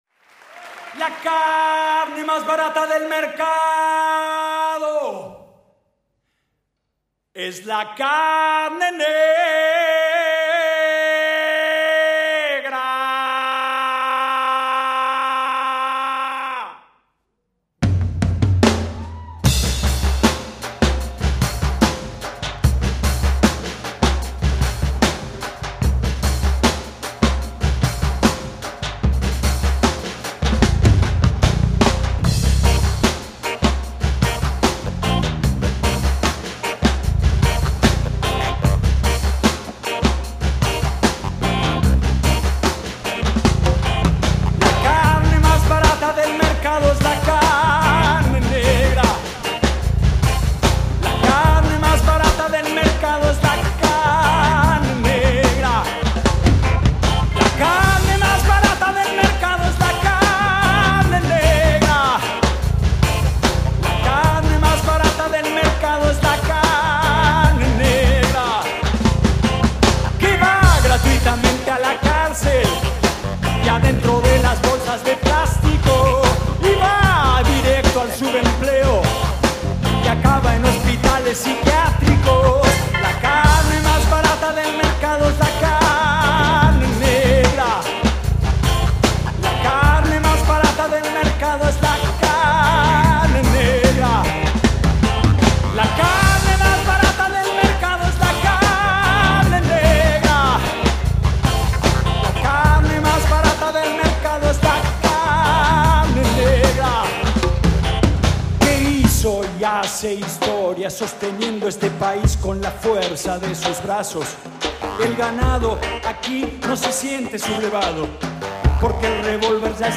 Recorded live in February 2005